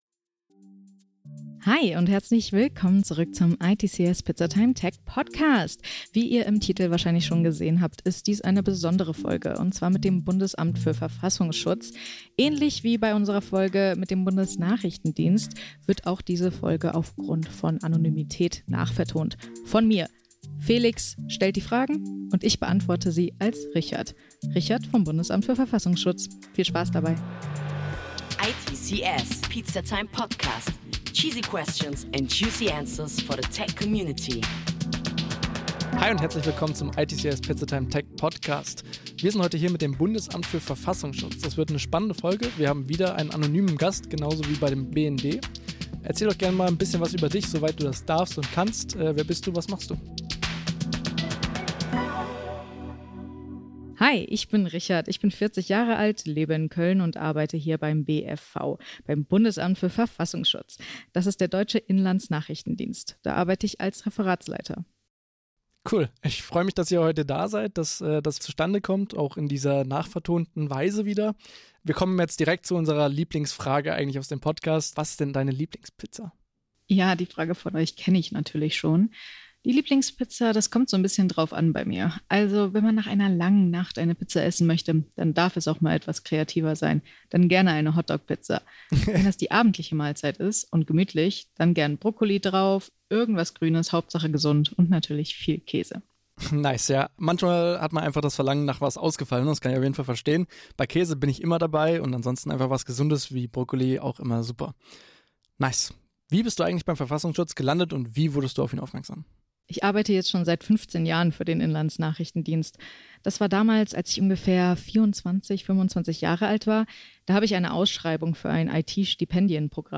In dieser besonderen Episode sprechen wir mit einem anonymen Gast aus der Cyberabwehr des BfV.
Anonym aufgenommen und nachvertont.